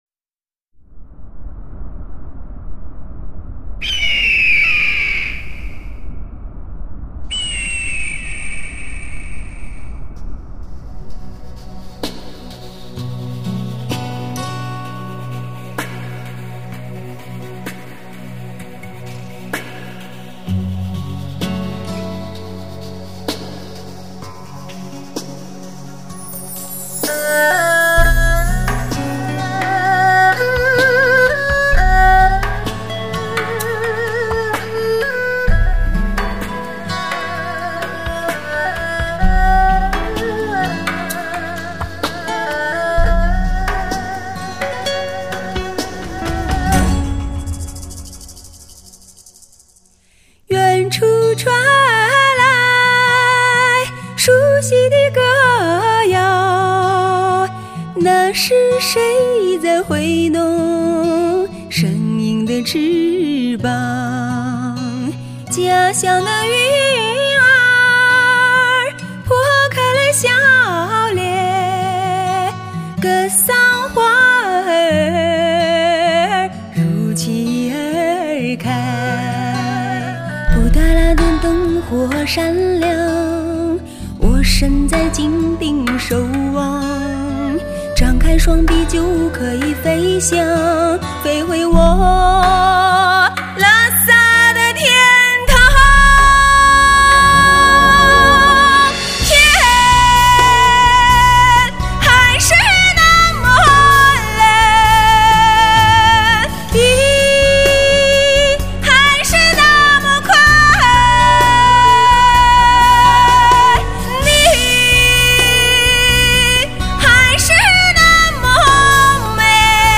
发烧唱片史上唯一草原风情蒙汉双语唱片。
最立体标准，最真实动人，最纯净细腻，更震撼典范。